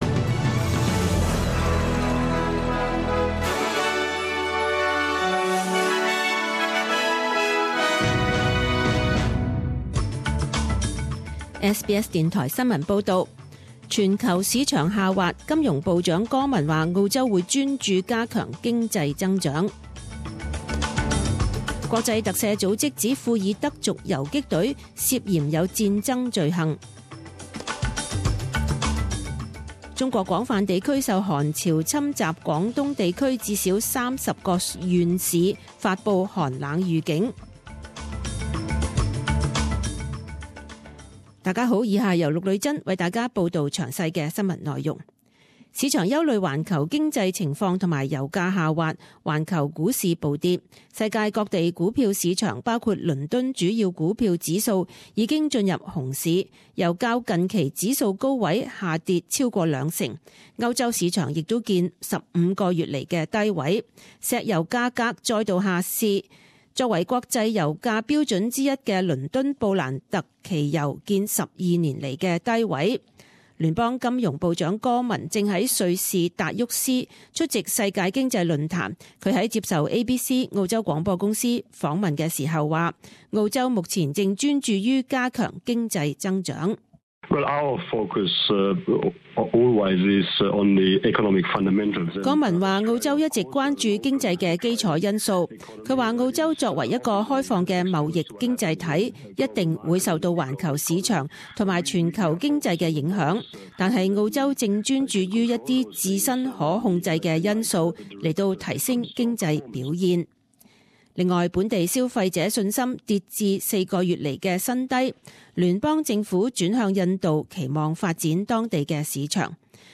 十点钟新闻报导（一月二十一日）